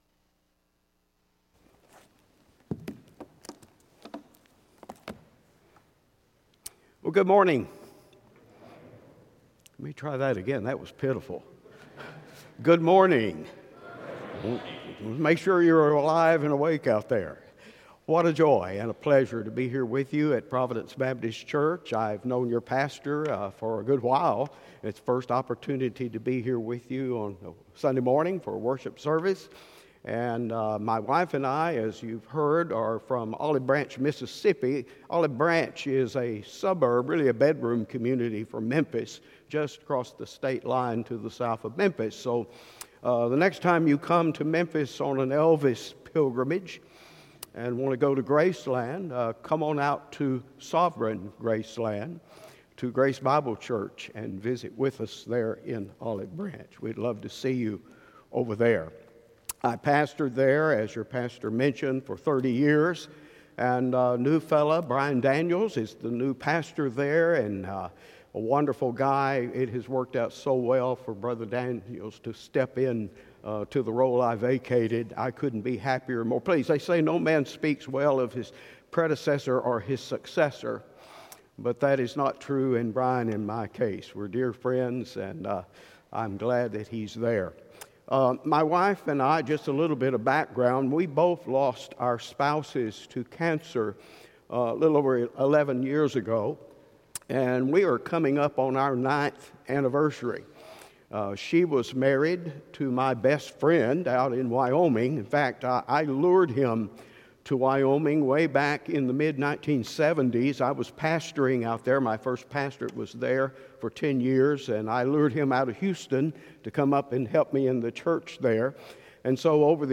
Weekly Sermons from Providence Baptist Church in Huntsville Alabama